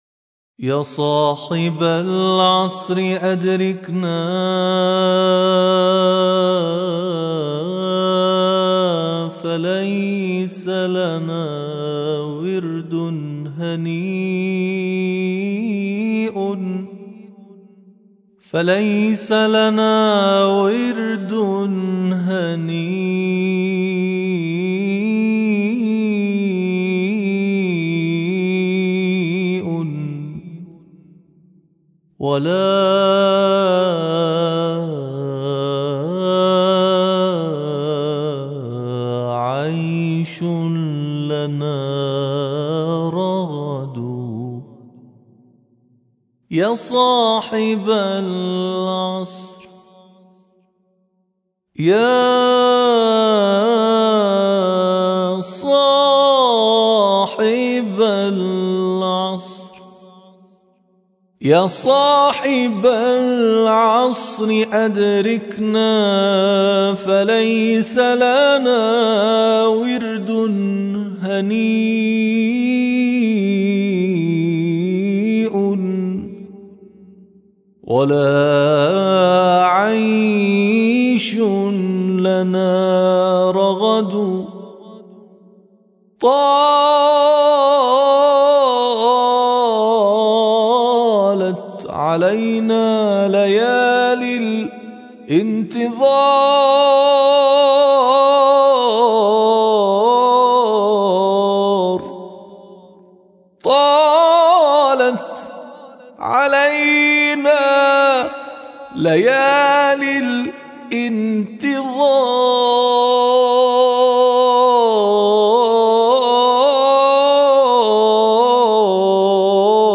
ابتهال